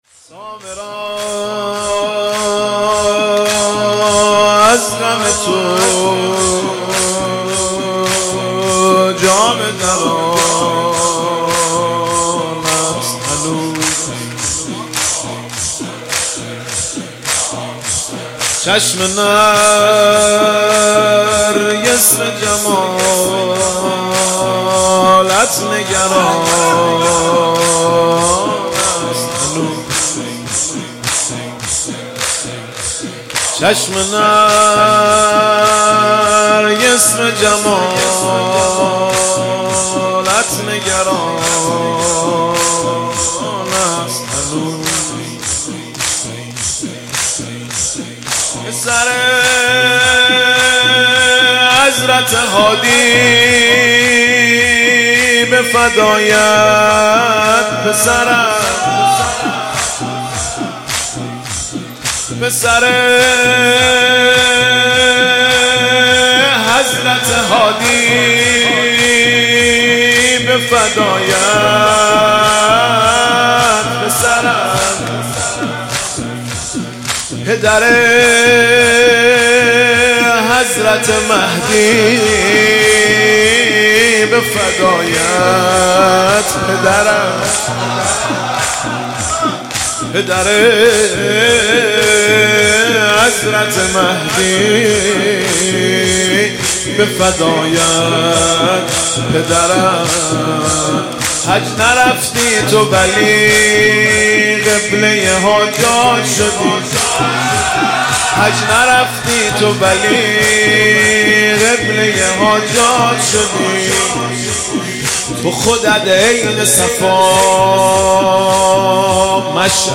مداحی دلنشین